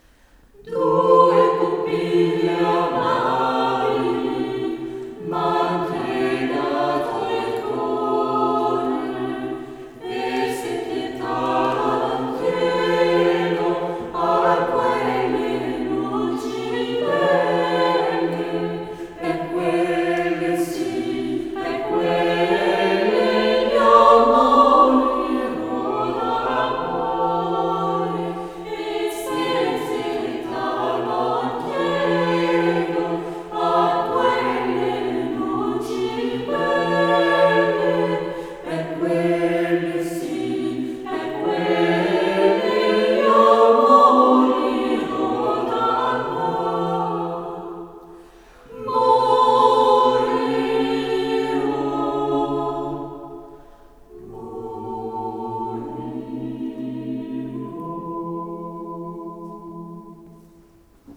Ensemble vocal Petits Bâtons – Musique vocale de chambre
L’ensemble vocal Petits Bâtons est un groupe de sept chanteurs amateurs.
Église de Saint-Rémy-lès-Chevreuse (06/07/2017)